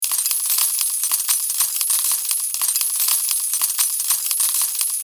Download Jackpot sound effect for free.
Jackpot